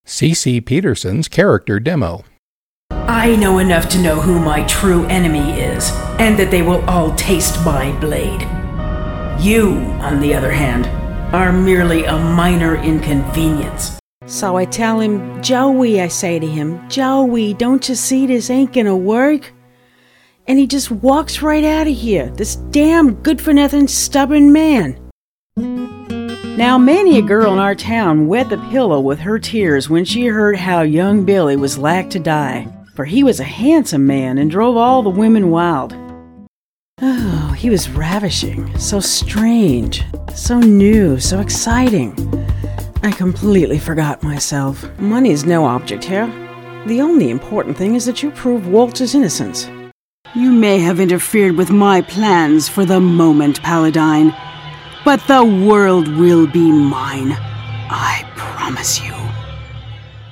Character Demo (1 min.)
These clips are from selected video games, radio plays and other entertainment projects.